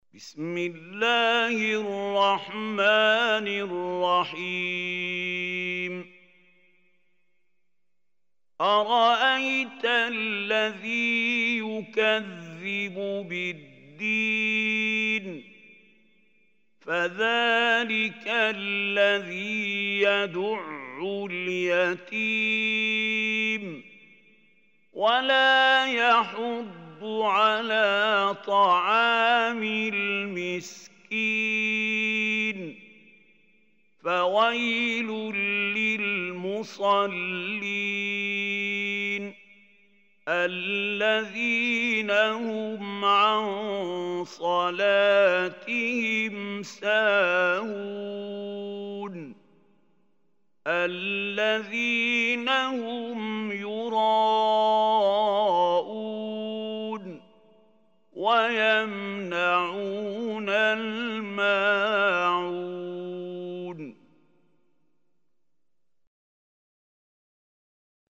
Surah Maun Recitation MP3 Mahmoud Khalil Hussary
Surah Maun is 107 surah of Holy Quran. Listen or play online mp3 tilawat / recitation in Arabic in the beautiful voice of Sheikh Mahmoud Khalil Hussary.